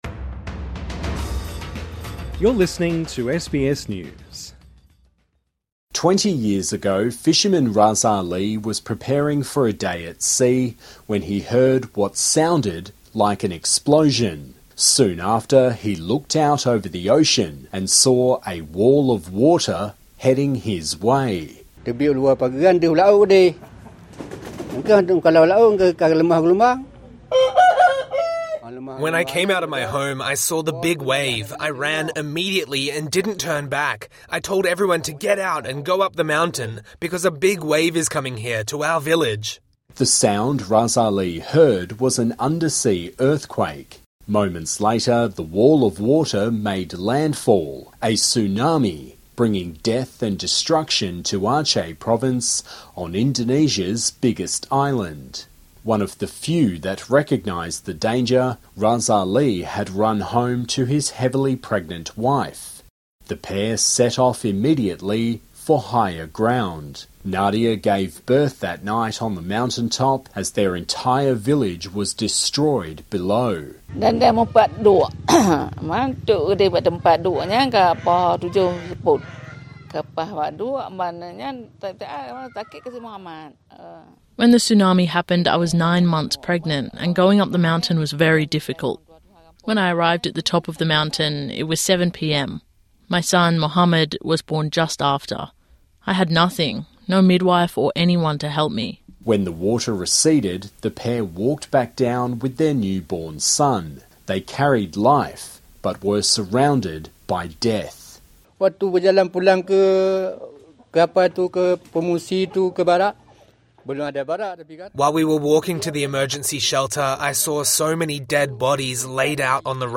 Survivors in Banda Aceh recall horrors of Boxing Day tsunami